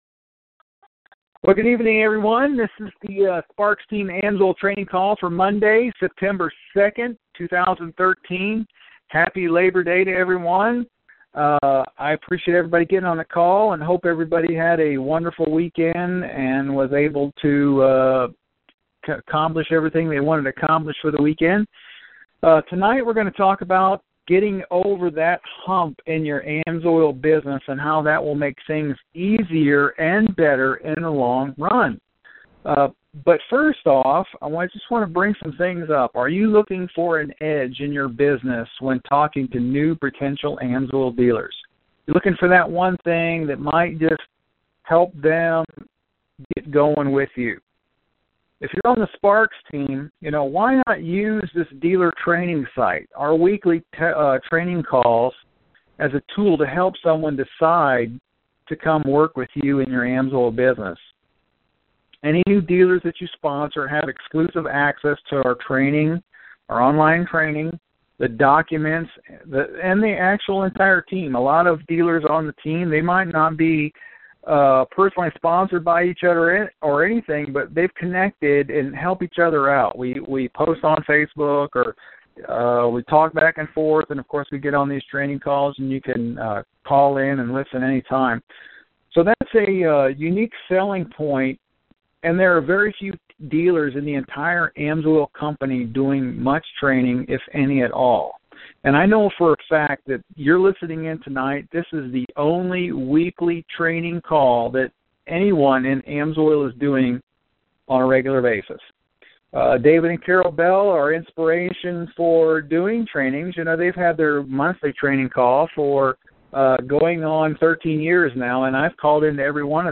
This weeks AMSOIL Training call we talk about getting over the hump in your business. Learn how to do this and you will see more success in your business.